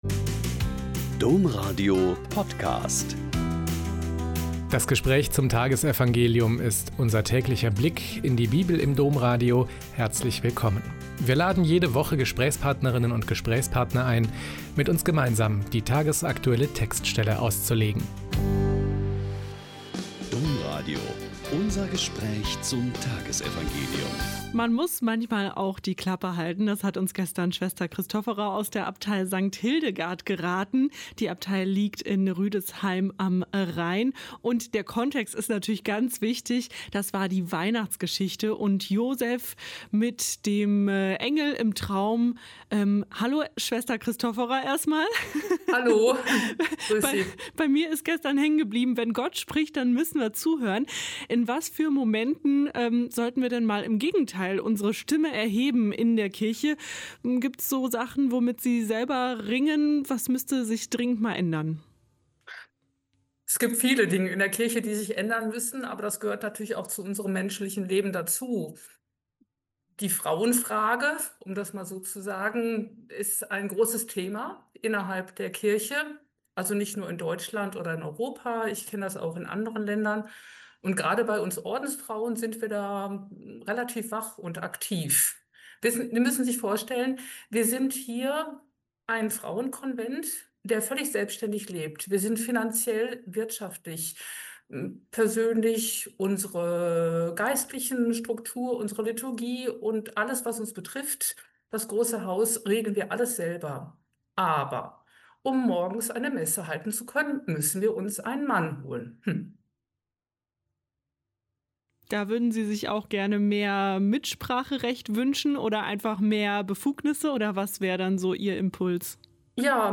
Lk 1,5-25 - Gespräch